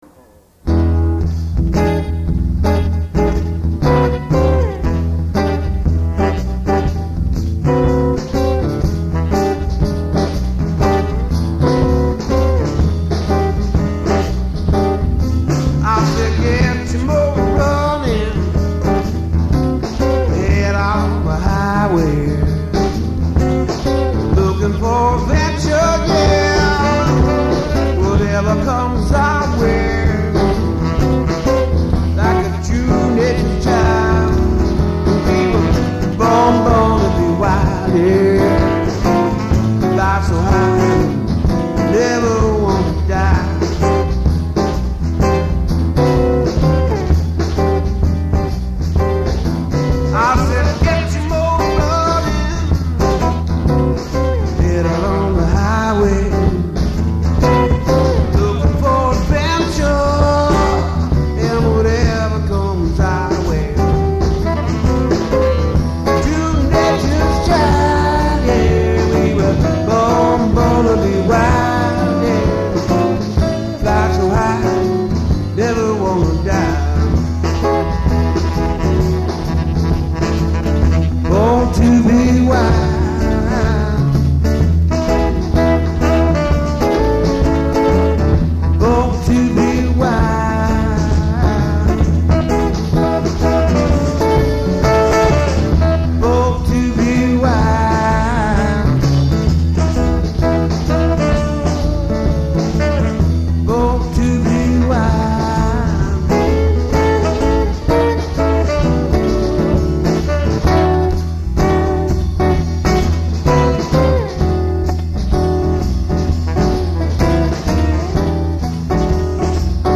Rock: